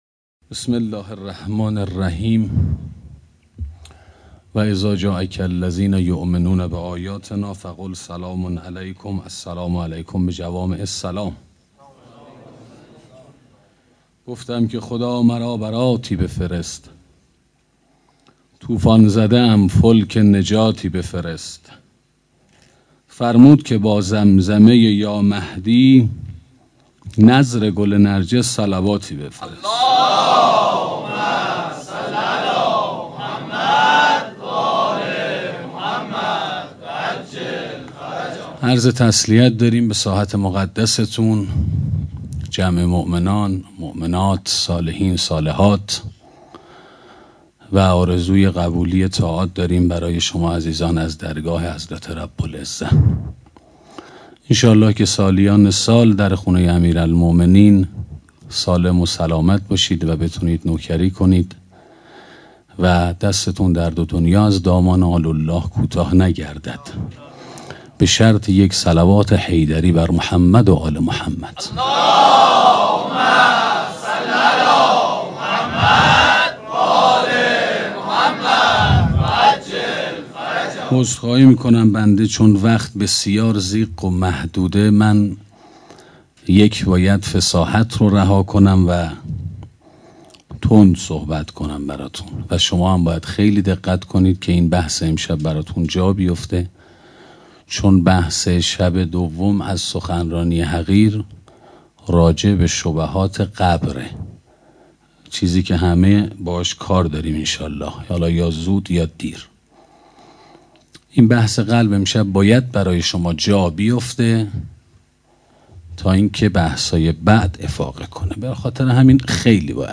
سخنرانی قلب سلیم